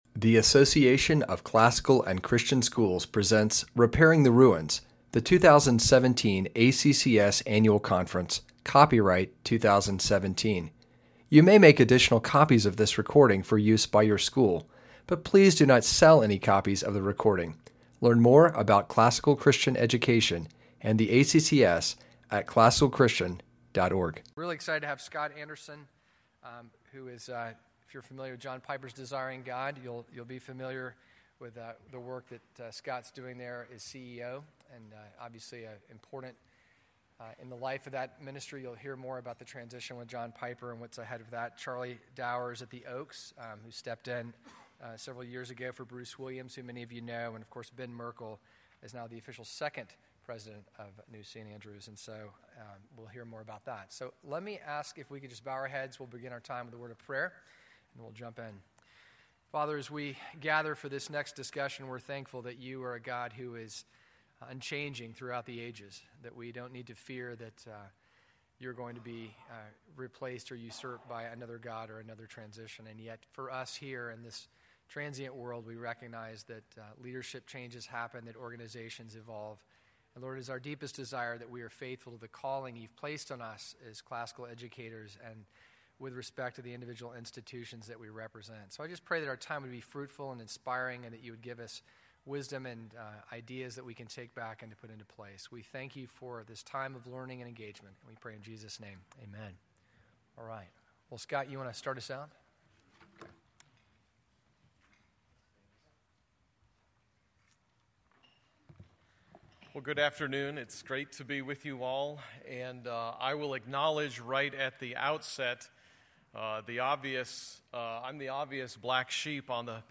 2017 Plenary Talk | 0:51:54 | Leadership & Strategic
Panel Discussion: From the Founders to the Succeeding Generation